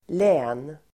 Uttal: [lä:n]